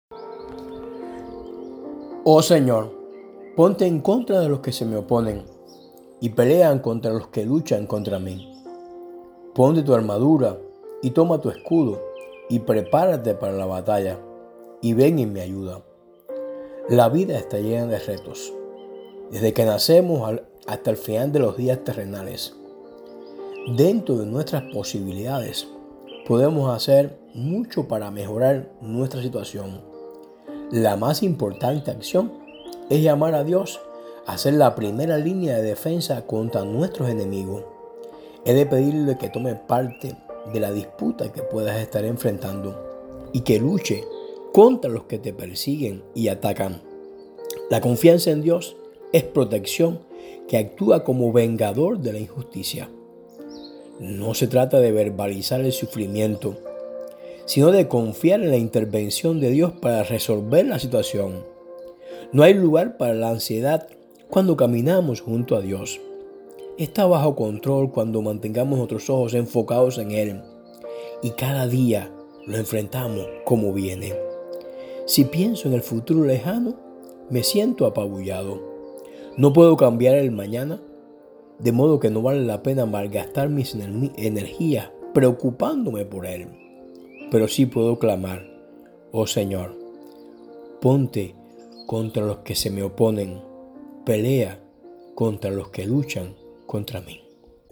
Mensajes – Del Pastor